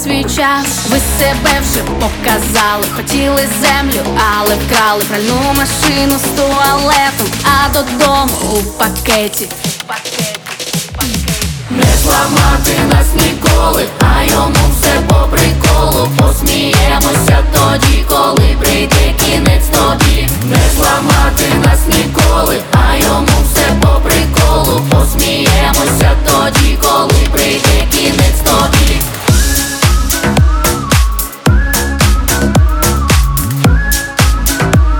Жанр: Хаус / Украинские